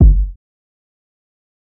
Kick 10.wav